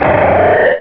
Cri d'Avaltout dans Pokémon Rubis et Saphir.